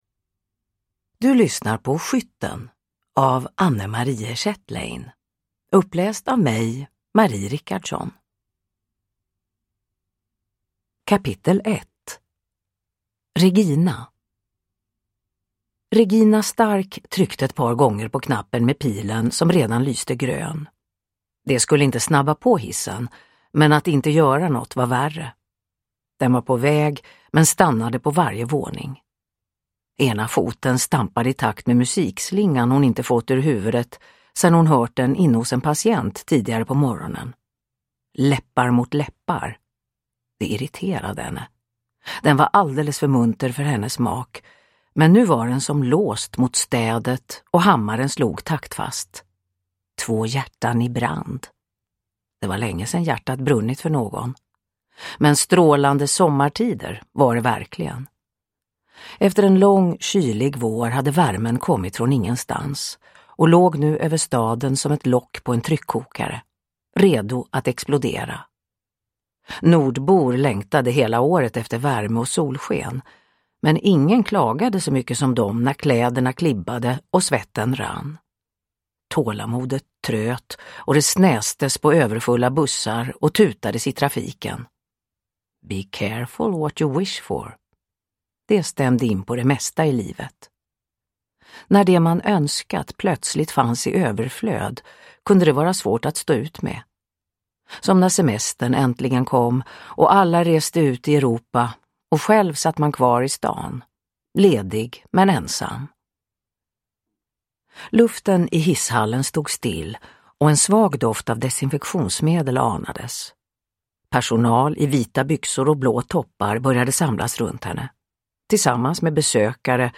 Skytten – Ljudbok